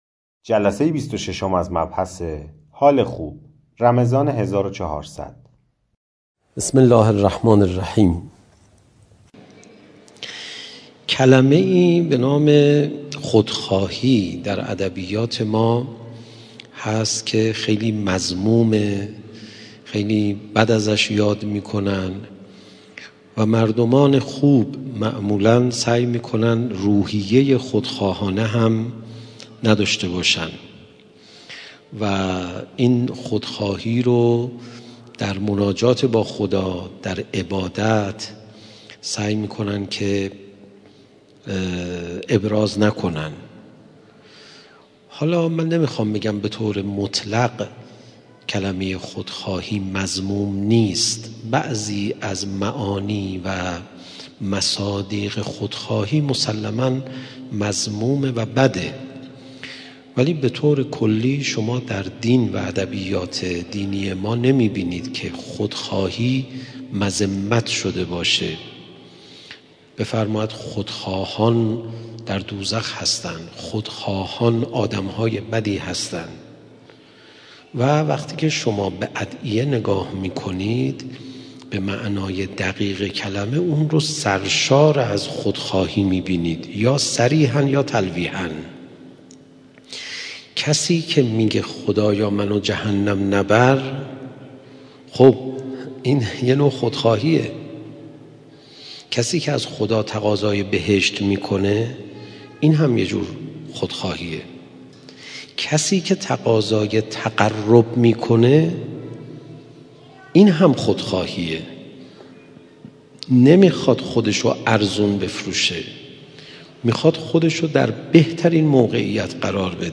دانلود بیست و ششمین قسمت از سلسله مباحث اخلاقی «حال خوب» استاد پناهیان؛ این بخش: ابعاد محبت خدا و تأثیر آن بر حال خوب